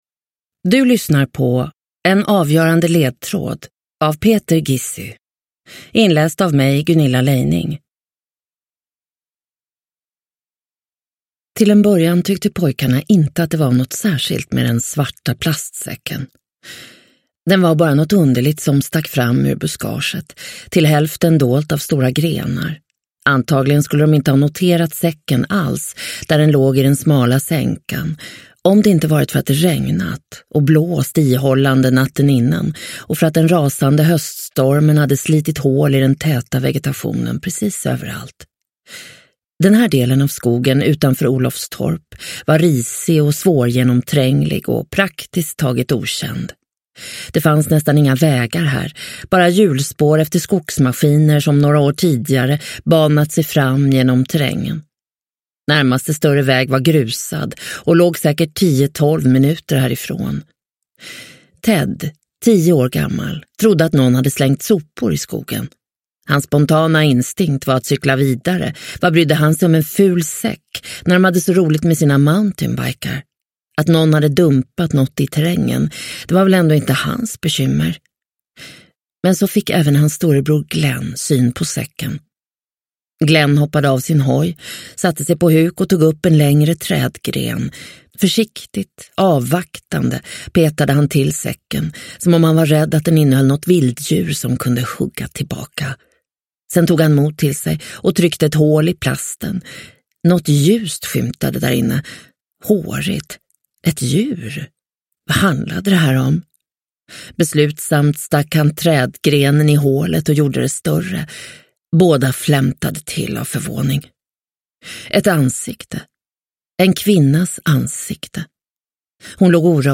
En avgörande ledtråd – Ljudbok – Laddas ner